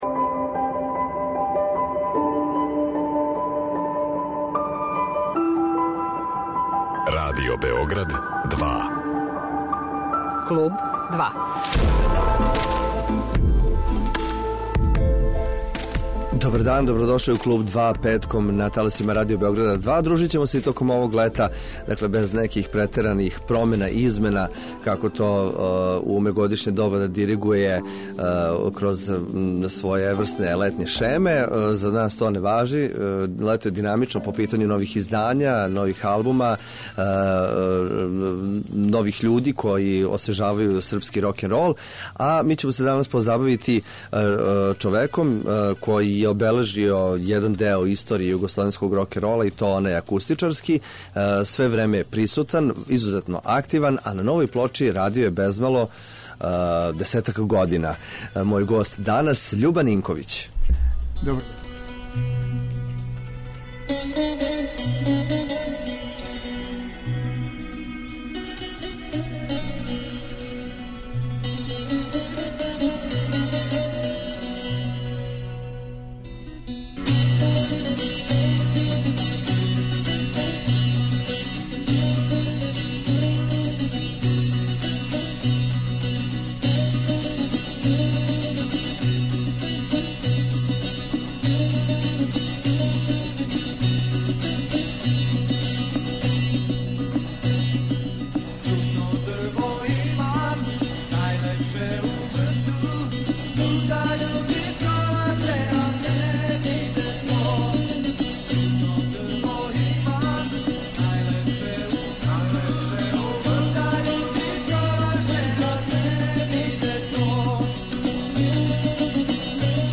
Коме се данас обраћа и на који начин сазнаћемо управо од нашег саговорника слушајући музику са албума који је настајао годинама.